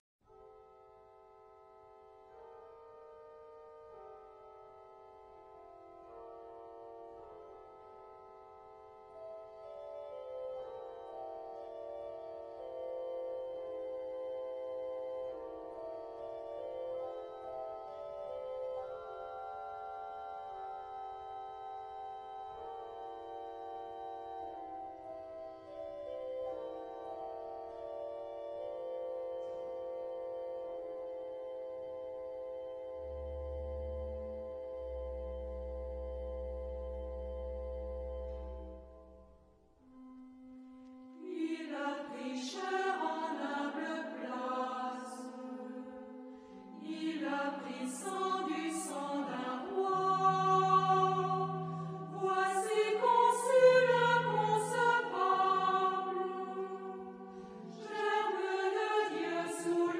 Type de choeur : unisson
Instrumentation : Orgue  (1 partie(s) instrumentale(s))
Tonalité : do majeur